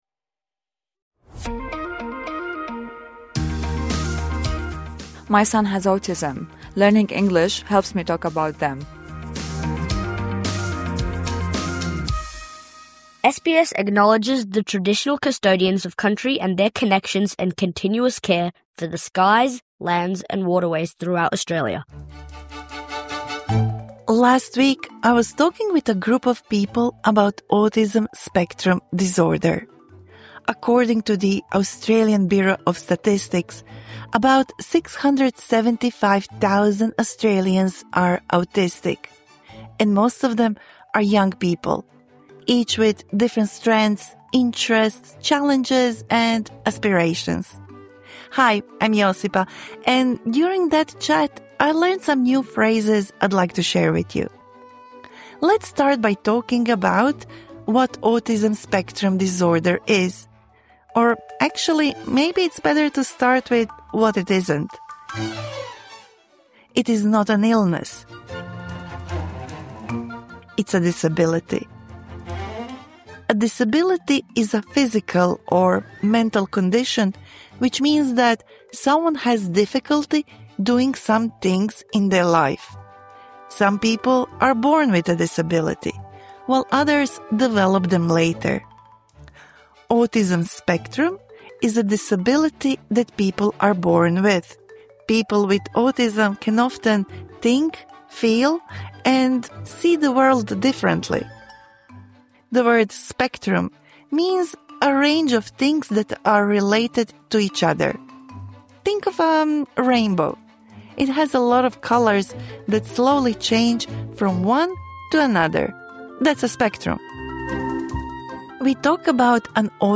This episode is for intermediate English language learners, to build vocabulary, confidence and understanding of this topic. The dialogue and phrases are one specific example of adults discussing a young child who was recently diagnosed.